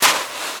STEPS Sand, Walk 04.wav